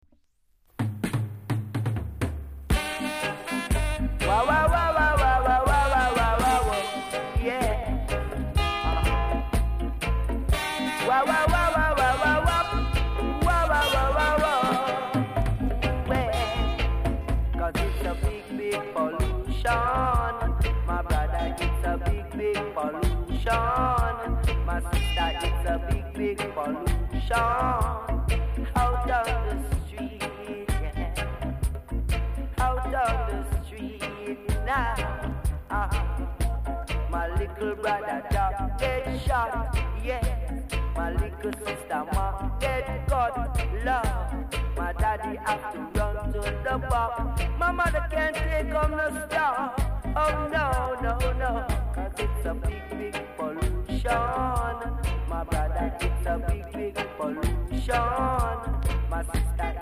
コメント ROOTS CLASSIC!!